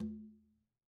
Quinto-HitN_v1_rr2_Sum.wav